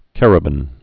(kărə-bən, kə-rēbən)